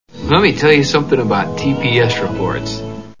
Office Space Movie Sound Bites